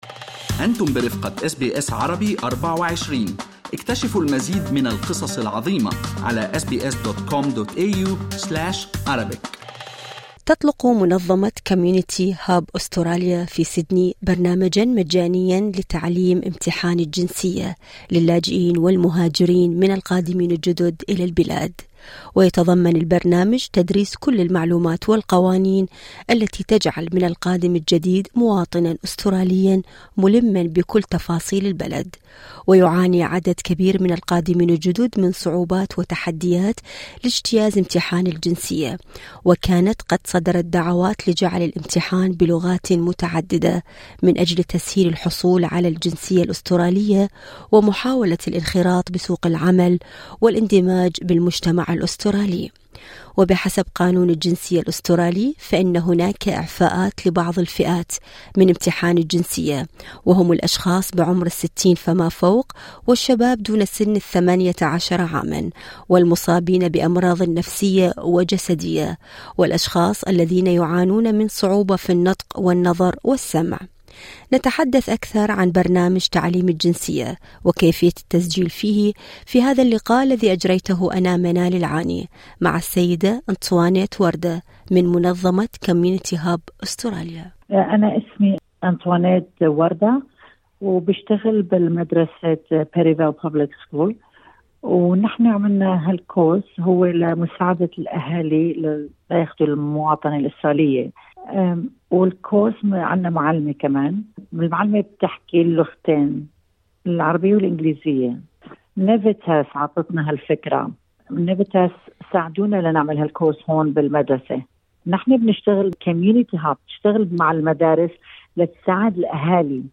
المقابلة الصوتية